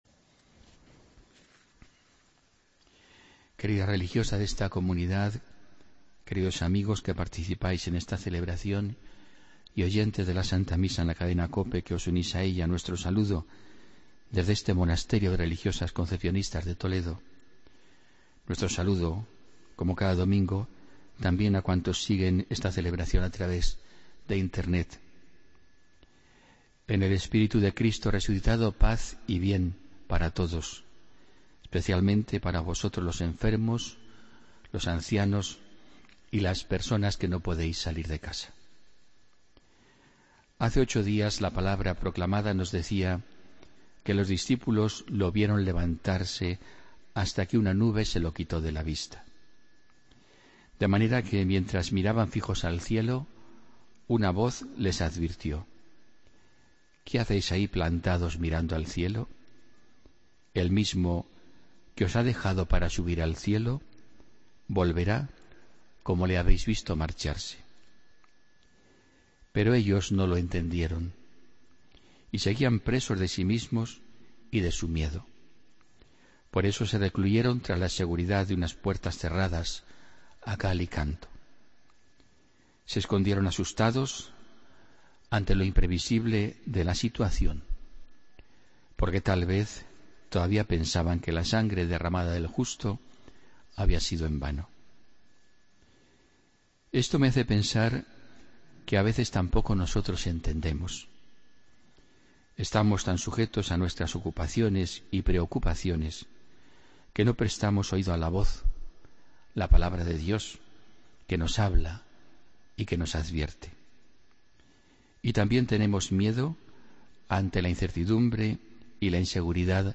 Homilía del domingo 15 de mayo de 2016